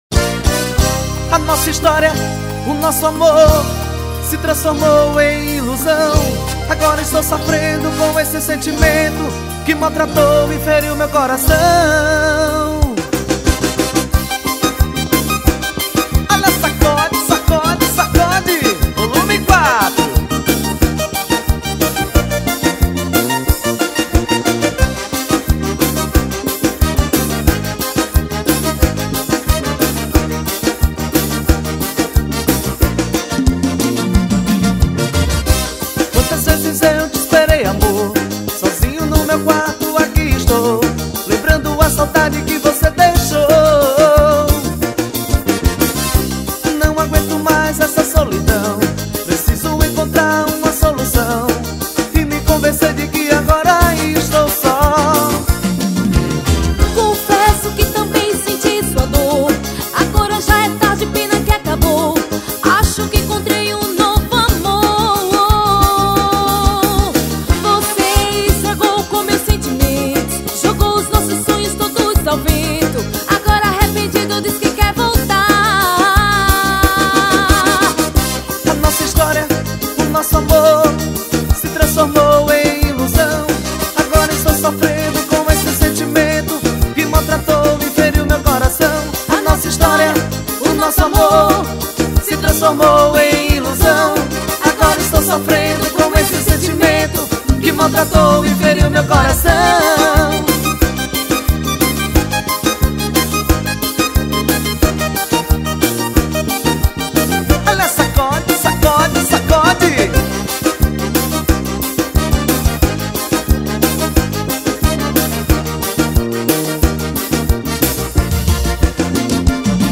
2024-12-29 14:05:59 Gênero: Forró Views